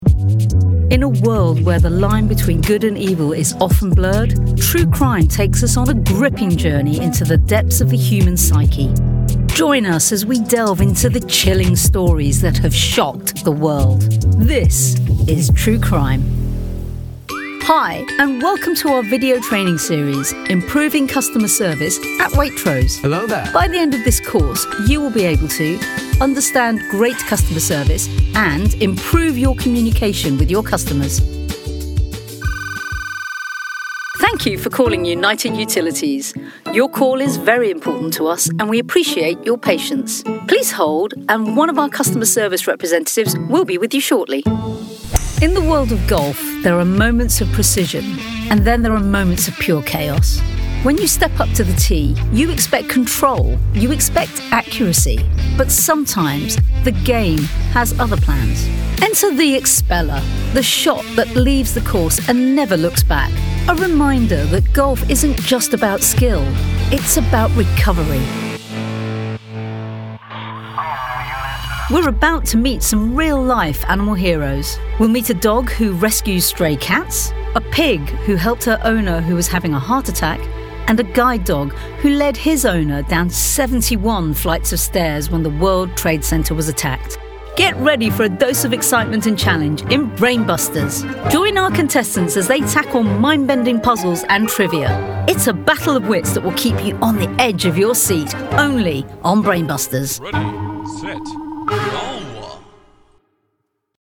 Radio & Voiceovers
I can provide voiceovers with a warm, clear and engaging voice which exudes confidence and professionalism. I have a slight maturity in my voice, as well as a youthful quality.
Corporate